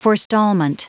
Prononciation du mot : forestallment
forestallment.wav